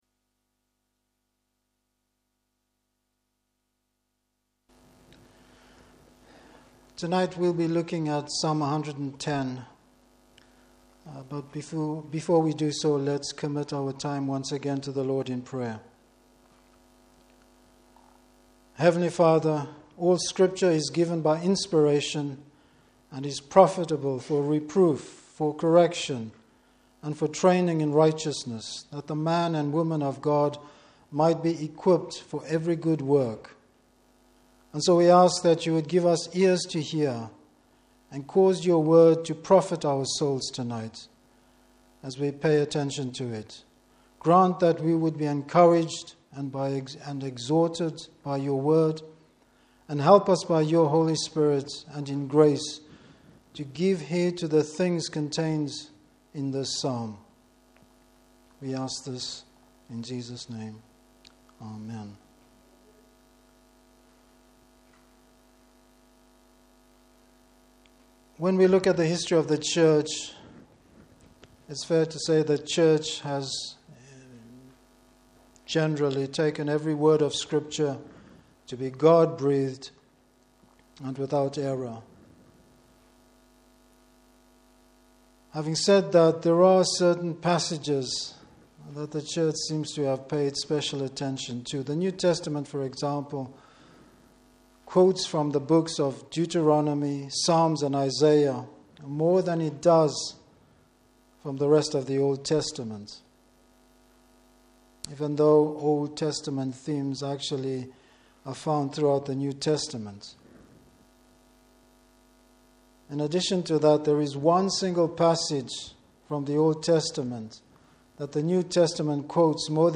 Service Type: Evening Service The Lord’s victory is a great reason to give thanks!